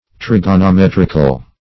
\Trig`o*no*met"ric*al\, [Cf. F. trigonom['e]trique.]
trigonometrical.mp3